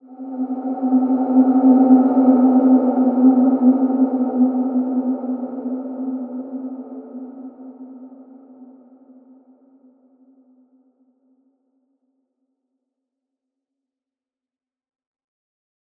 Large-Space-C4-f.wav